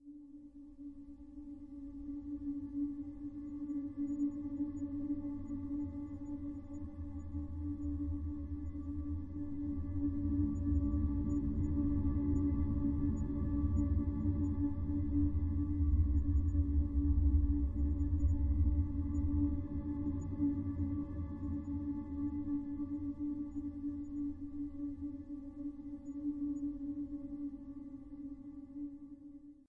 拉伸的声音 " NEPTUN Solo04 拉伸的声音
标签： 频率的最-行星 唱歌 冥想 拉伸 听起来换和平 放松 头脑 西藏 愈合声音 海王星 副大胆软件
声道立体声